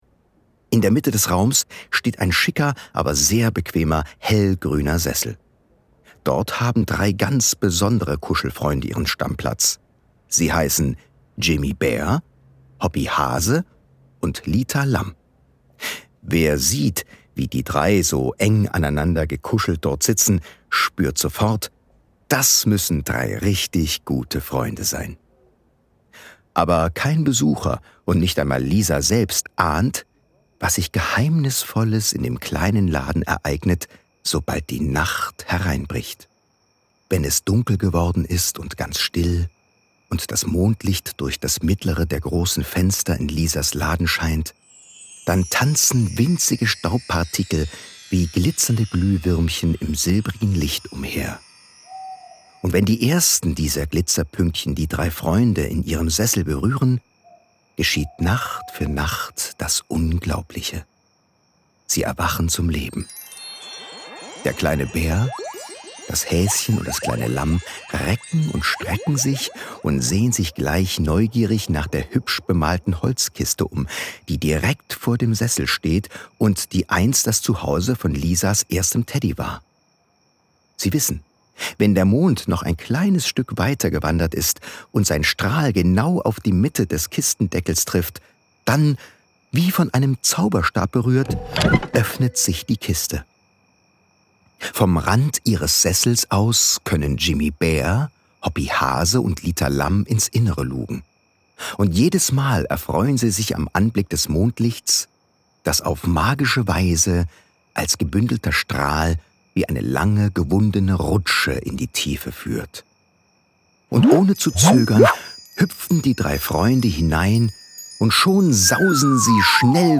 Hörspiel mit Liedern